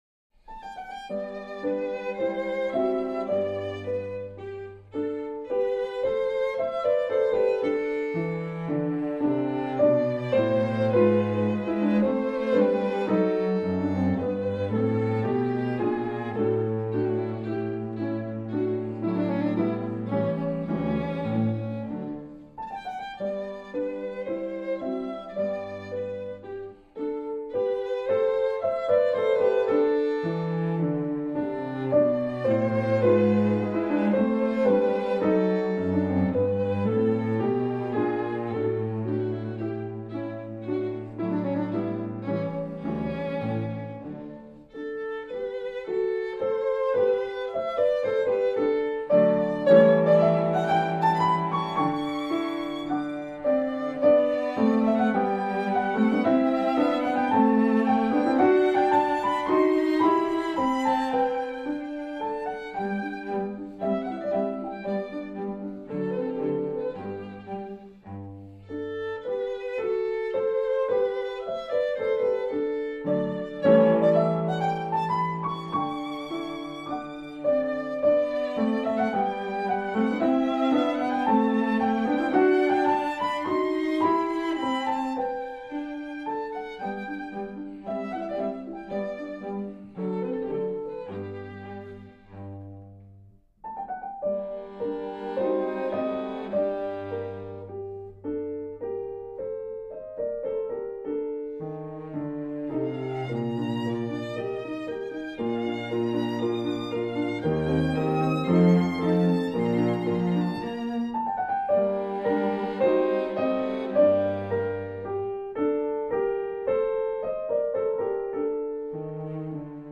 скрипка
виолончель
фортепиано